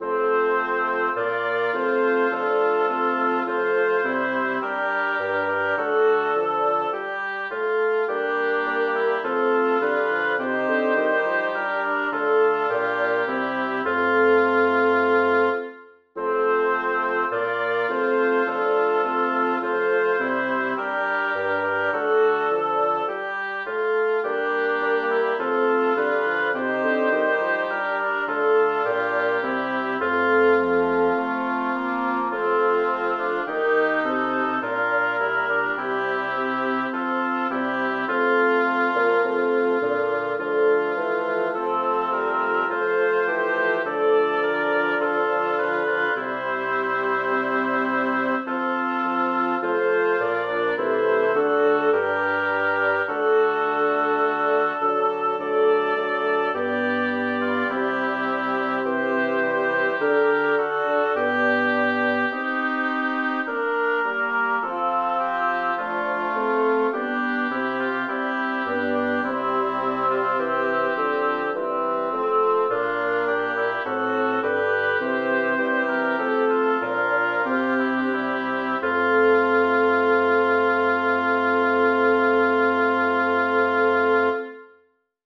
Title: An Wasserflüssen Babylon Composer: Johann Stobäus Lyricist: Wolfgang Dachstein Number of voices: 5vv Voicing: SATTB Genre: Sacred, Chorale
Language: German Instruments: A cappella